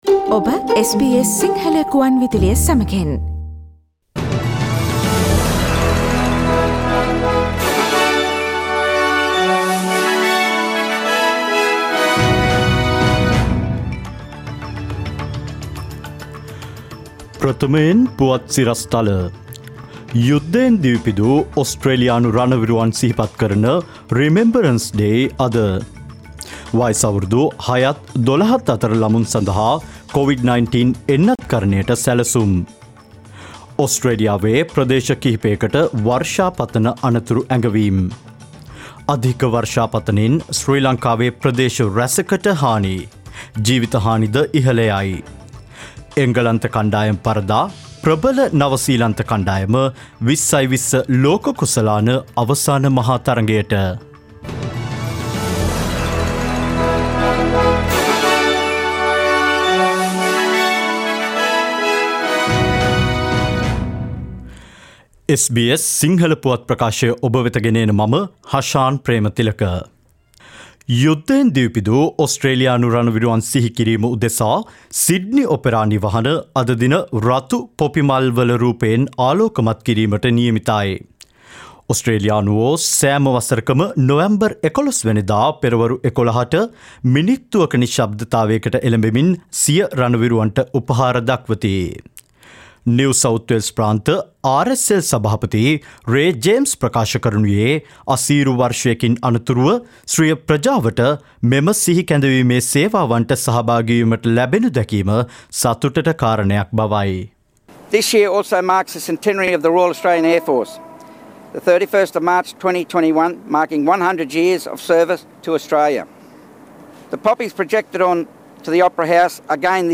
නොවැ 11දා SBS සිංහල පුවත් : ඔස්ට්‍රේලියානු යුධ විරුවන් වෙනුවෙන් ඔපෙරා නිවහන පොපි මලින් සැරසේ.
2021 නොවැම්බර් මස 11 වැනි බ්‍රහස්පතින්දා SBS සිංහල පුවත් ප්‍රකාශයට සවන් දීමට ඉහත ඡායාරූපය මත ඇති speaker සළකුණ මත click කරන්න.